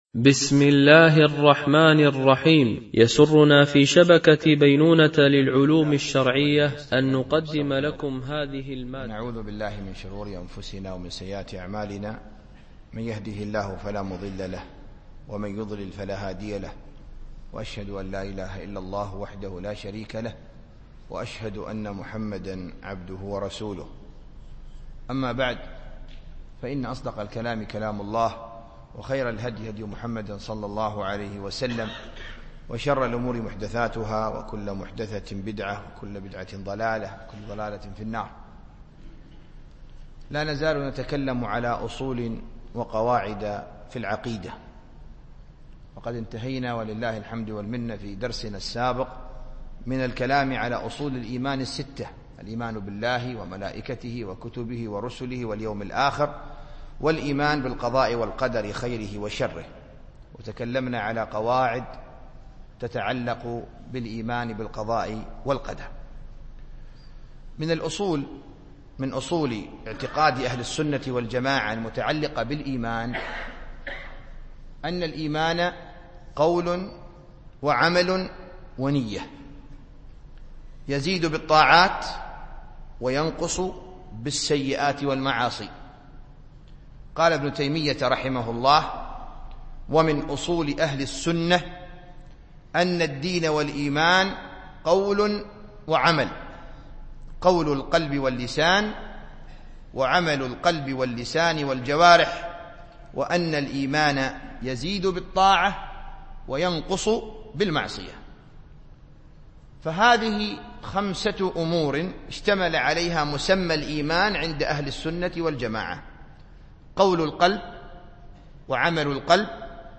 سلسلة أصول و قواعد في العقيدة ـ الدرس الثاني عشر
التنسيق: MP3 Mono 22kHz 32Kbps (CBR)